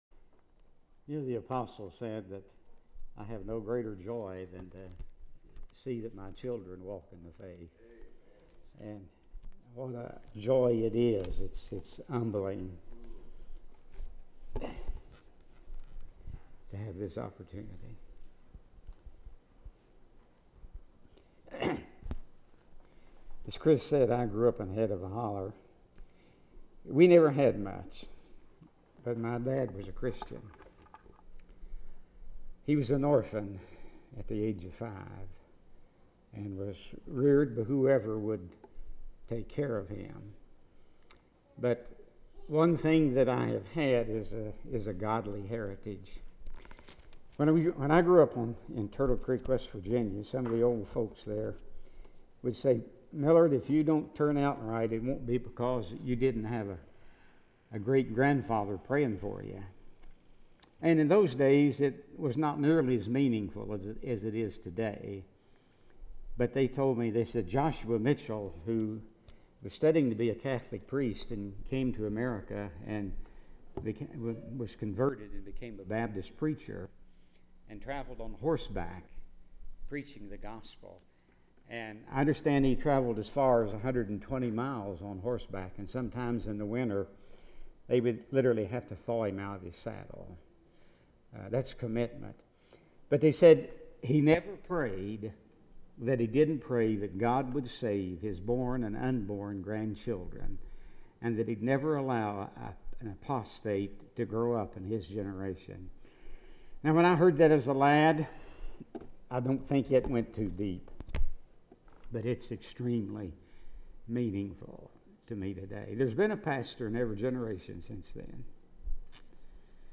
Service Type: Revival 2012